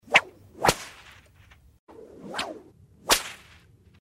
Вы можете слушать и скачивать резкие щелчки, свистящие удары в воздухе, отчетливые хлопки по поверхности.
Звук взмаха и удара кнутом